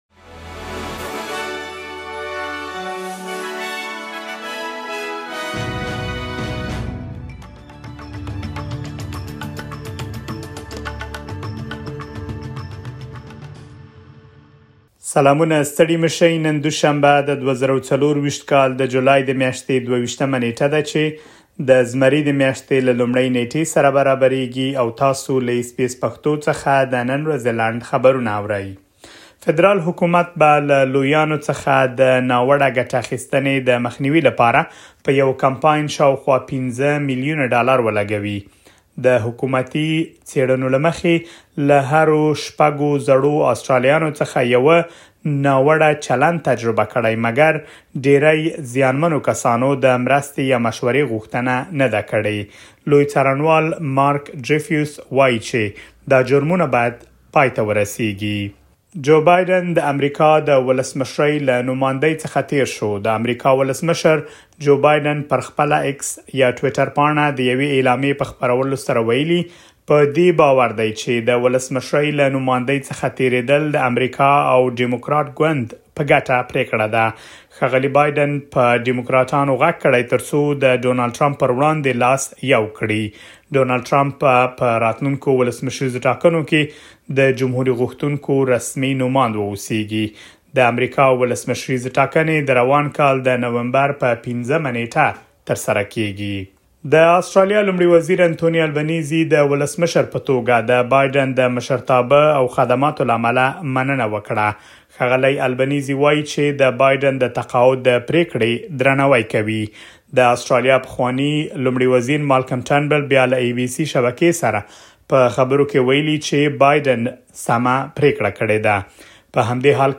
د اس بي اس پښتو د نن ورځې لنډ خبرونه|۲۲ جولای ۲۰۲۴